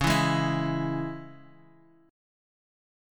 C#m chord